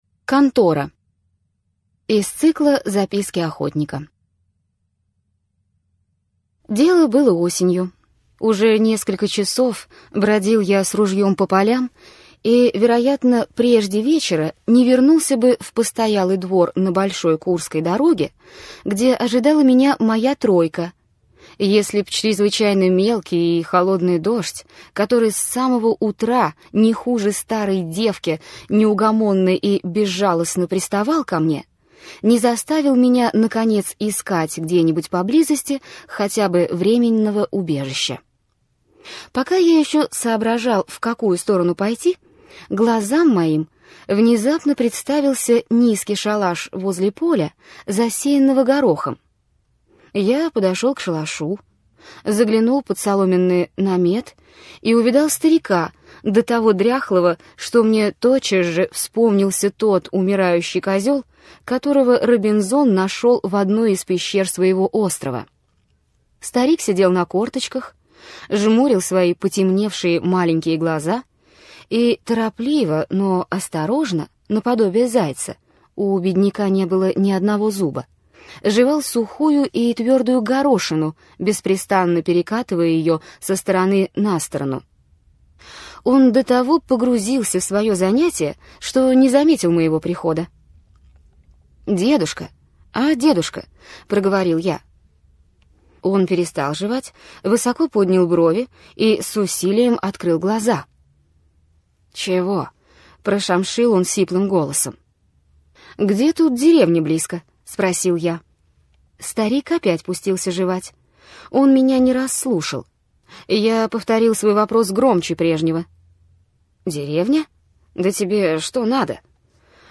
Аудиокнига Из записок охотника: Касьян с Красивой мечи. Конец Чертопханова. Контора | Библиотека аудиокниг